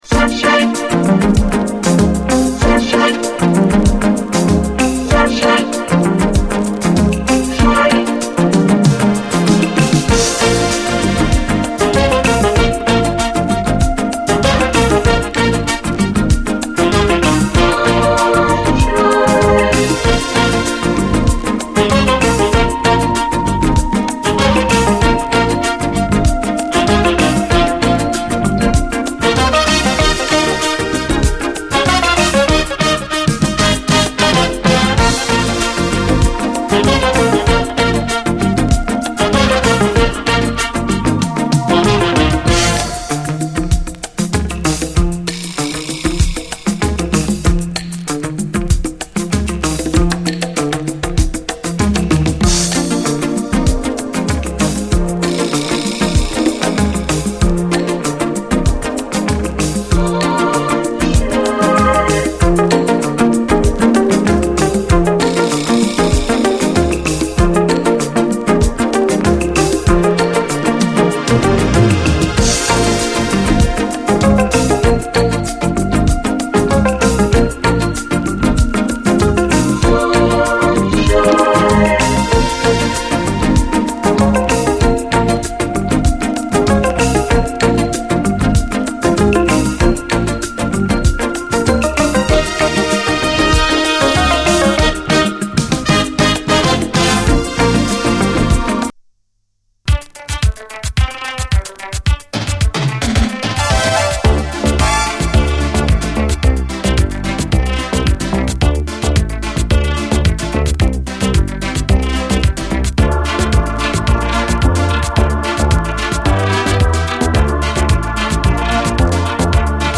DISCO
ロボ声入りのメロウ・バレアリック・トラック